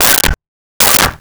Dog Barking 09
Dog Barking 09.wav